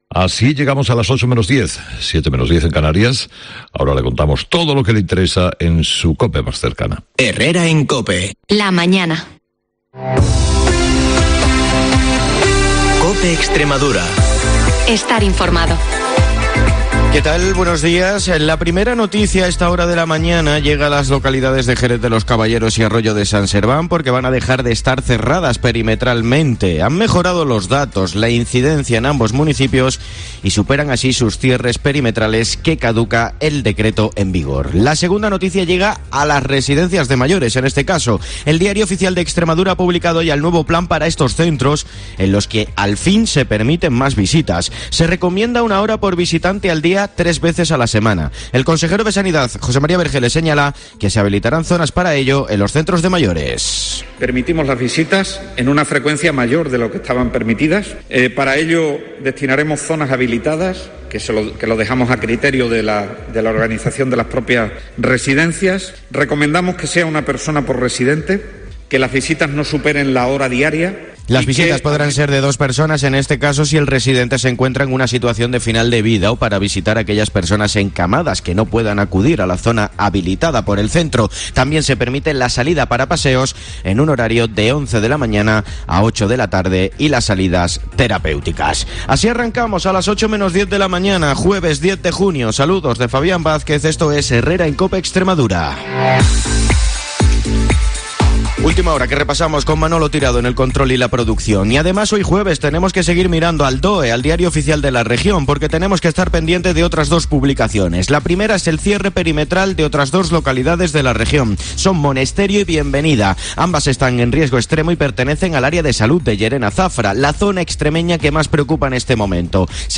el informativo más escuchado de la radio en Extremadura